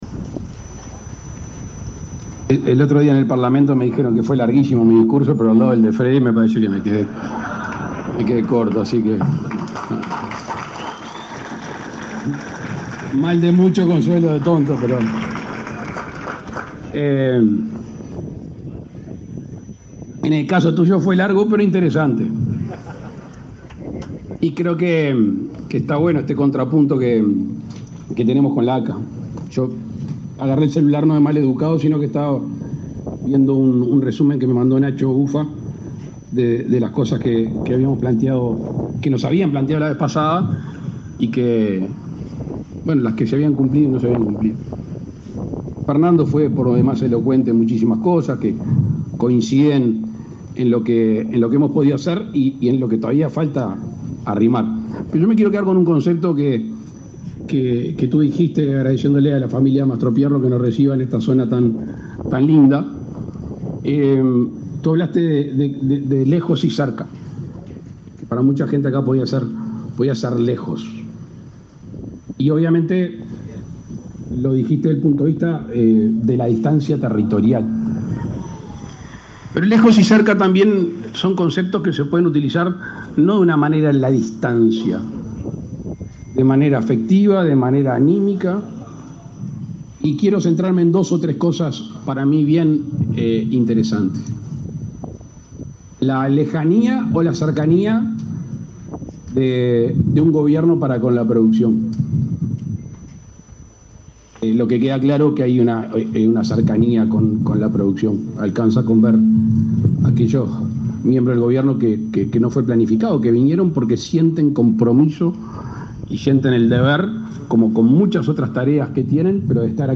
Palabras del presidente Luis Lacalle Pou
El presidente de la República, Luis Lacalle Pou, encabezó este lunes 6 en Rivera el acto de inauguración de la cosecha de arroz 2023.